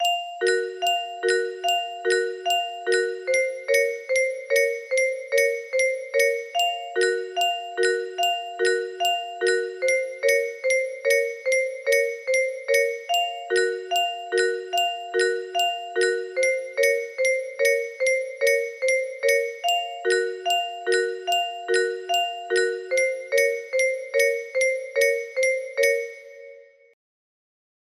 Unknown Artist - Untitled music box melody
Grand Illusions 30 music boxes More